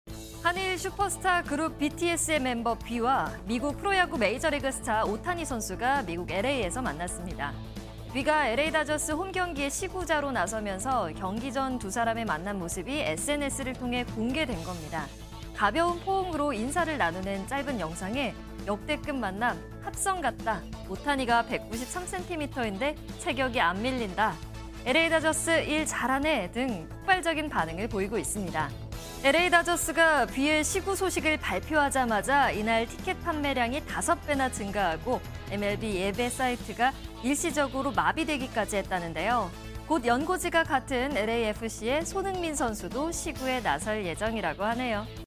MBN News Day 2 talking about BTS's V and Otani's 'Worldwide Hug' Delights Fans Worldwide